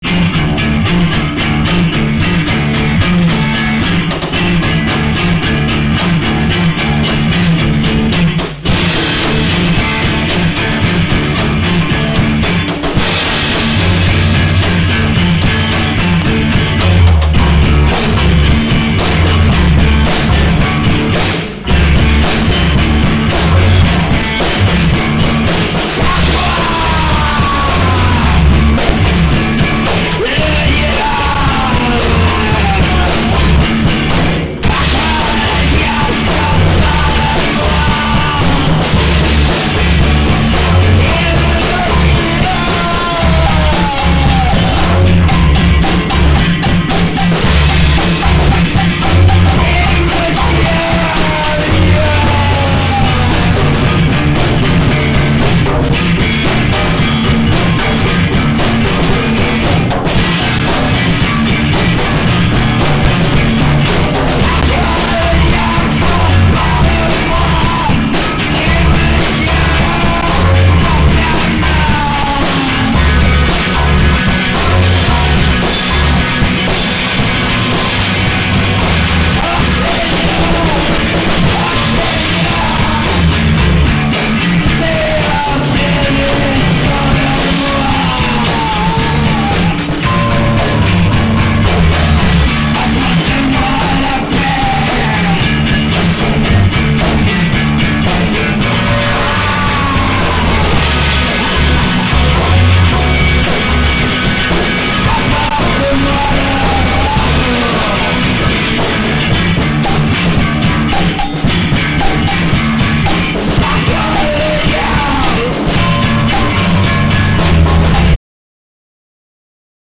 free-rock improvisé, basse, guitare, chant, batterie
improvised free-rock, bass, guitar, voice, drums